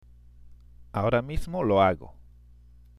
（アオラ　ミスモ　ロアゴ）